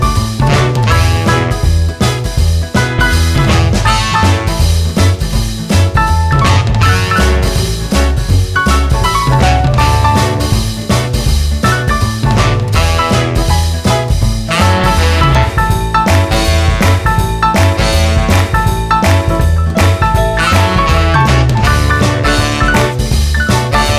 Minus Sax Solo Soul / Motown 2:44 Buy £1.50